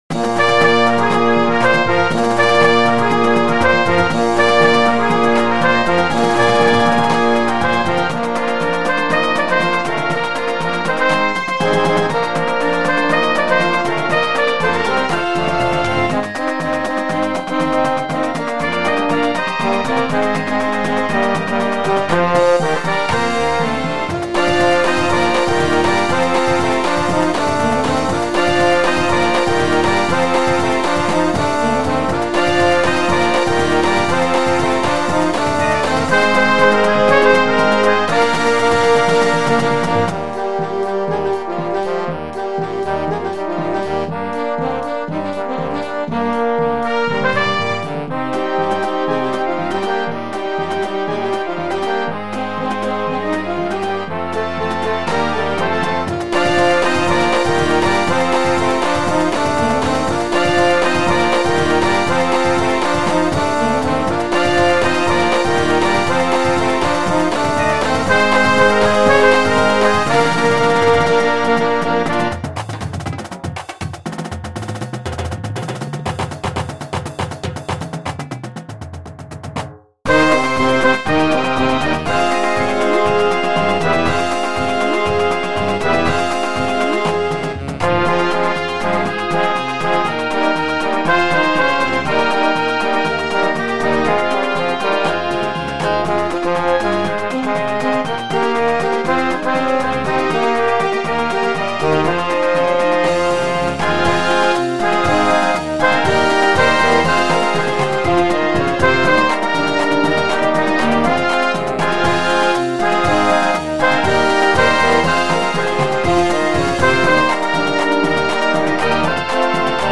2024_circle_drill_medley_-_with_percussion.mp3